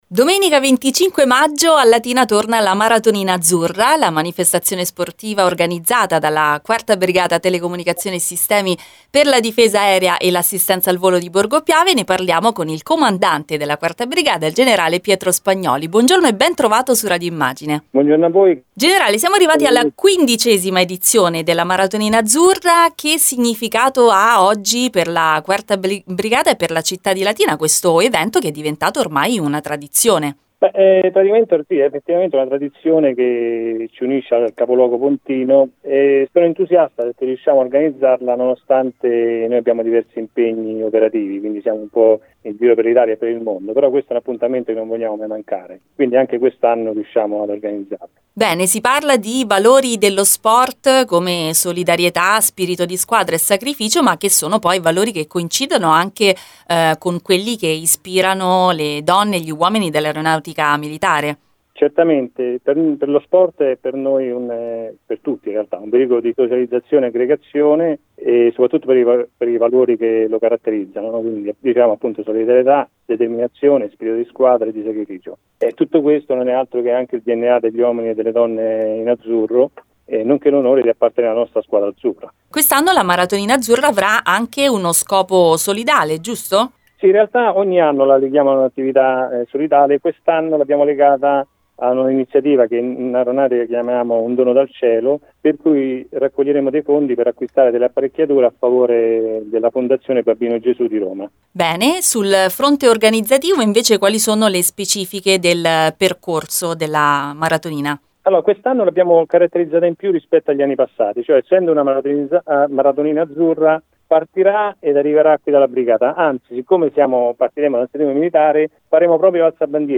La Maratonina Azzurra non è solo una corsa, ma un’occasione per celebrare e promuovere i valori che guidano quotidianamente l’impegno dell’Aeronautica Militare, come ha spiegato il Comandate della 4ª Brigata, Generale Pietro Spagnoli ai microfoni di Radio Immagine.